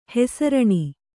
♪ hesaraṇi